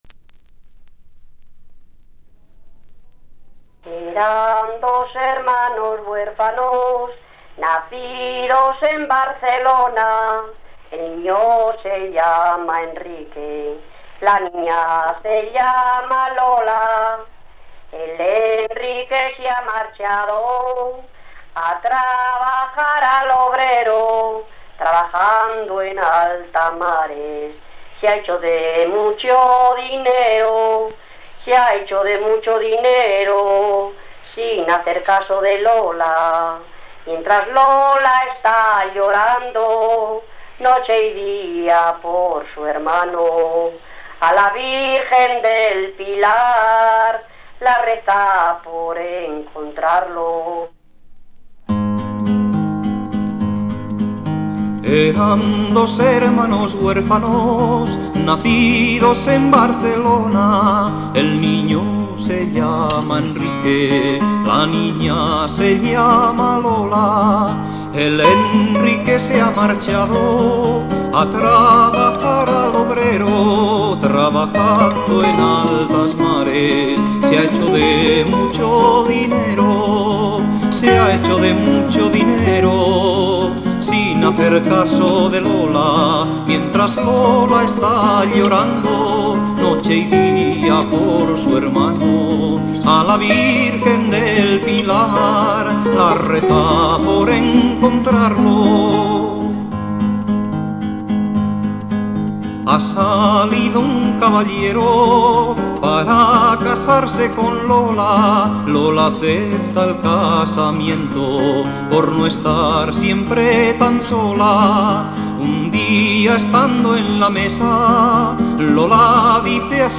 Laud
Guitarra